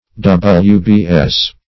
wbs - definition of wbs - synonyms, pronunciation, spelling from Free Dictionary